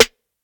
Max Baby Snare.wav